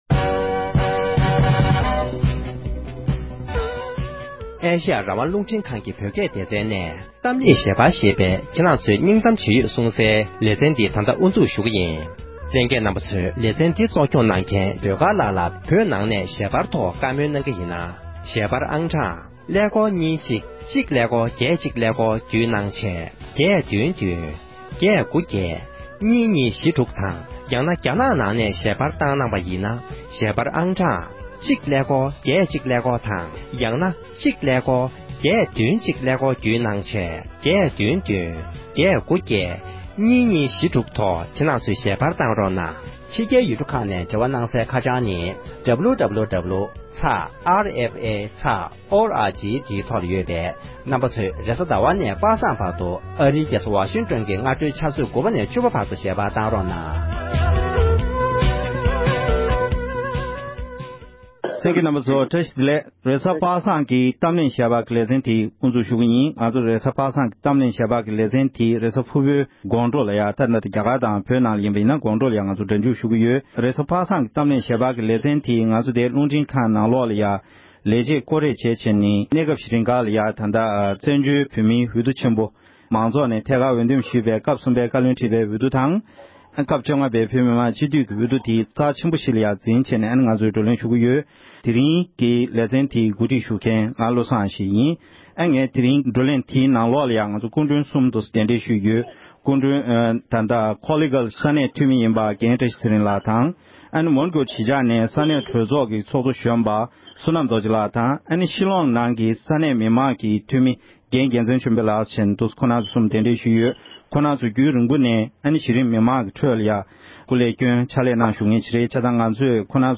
འབྱུང་འགྱུར་བཀའ་སྤྱི་གཉིས་ཀྱི་འོས་བསྡུ་ཐད་གླེེང་མོལ།